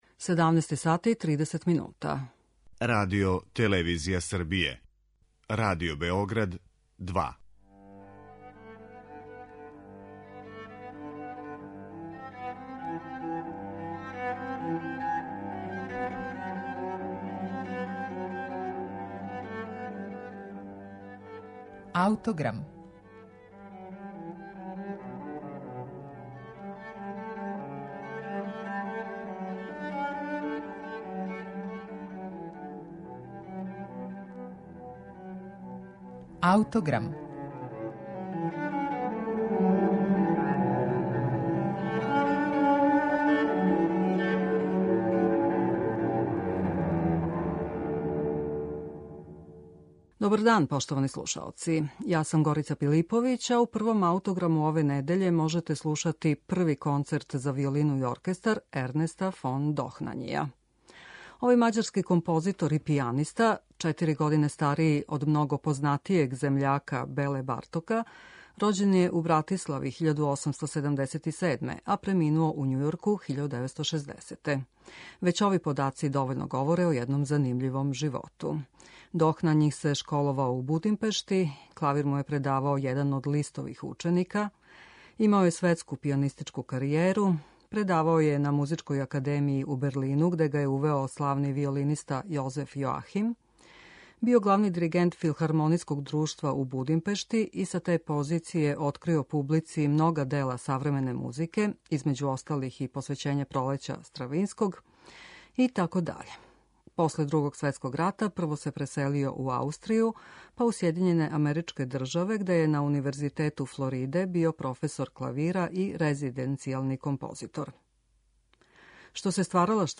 Мађарски композитор из прошлог века Ернест фон Дохнањи компоновао је, између осталог, два концерта за виолину и оркестар.
За разлику од својих великих савременика Беле Бартока и Золтана Кодаја, Дохнањи је остао чврсто укорењен у романтичарској традицији.